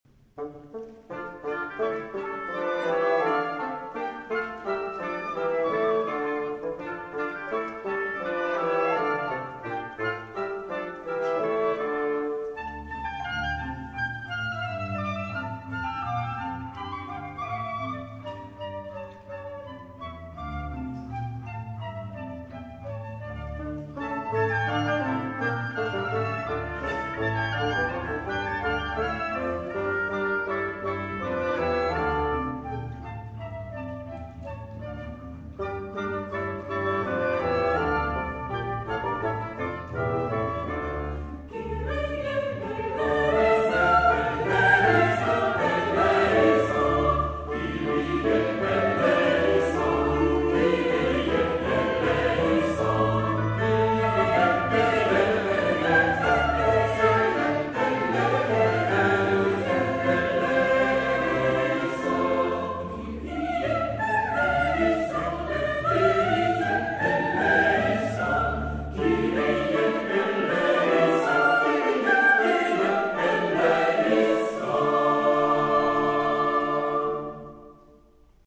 Quelques extraits de concerts des dernières années
Eglise Sainte Jeanne d’Arc, rue Salengro à Tours
La célèbre « Messe de minuit » de Charpentier et « Laudes à la Nativité » de Respighi avec orchestre « champêtre ».